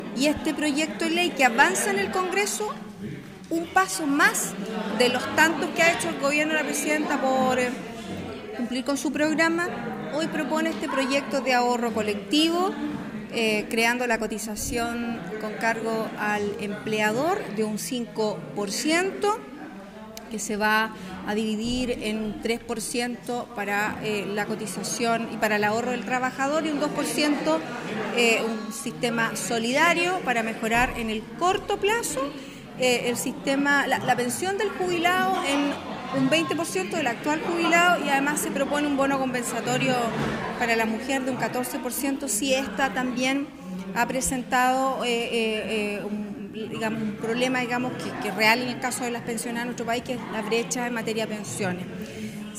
En materia de difusión del Nuevo Sistema de Ahorro Colectivo, se realizó un conversatorio organizado por la Secretaria Regional Ministerial del Trabajo y Previsión Social e INSFUDECH, el cual se efectuó en la Sede de Unión Comunal de Juntas de Vecinos de la comuna de Puchuncaví, ocasión en la cual estuvieron presentes los dirigentes vecinales de dicha comuna.
Seremi del Trabajo y Previsión Social, Karen Medina.